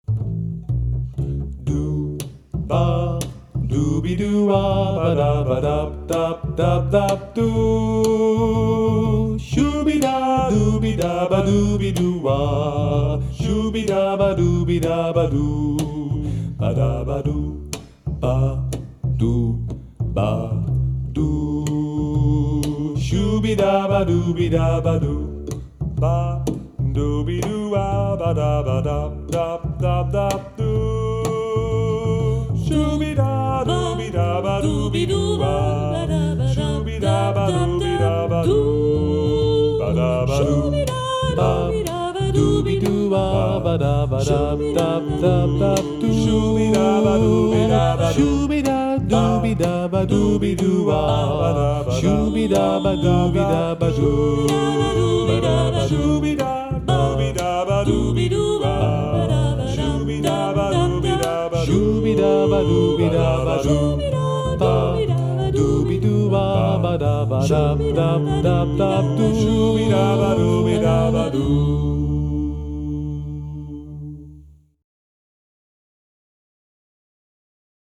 scatten